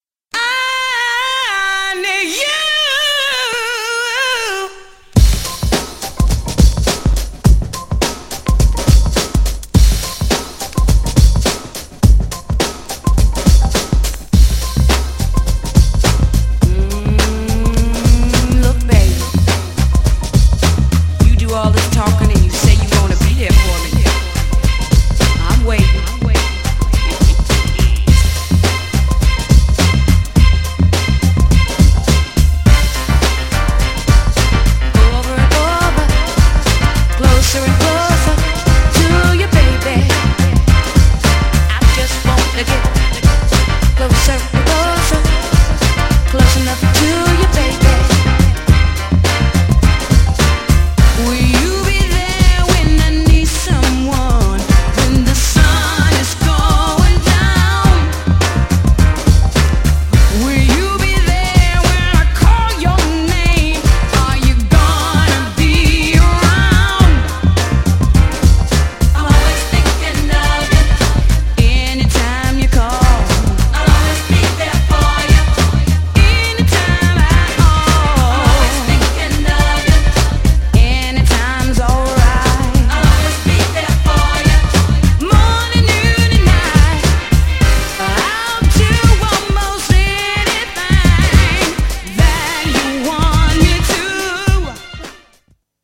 ダウンテンポのR&B MIXもクール!!
GENRE House
BPM 101〜105BPM